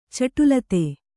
♪ caṭulate